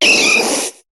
Cri de Machoc dans Pokémon HOME.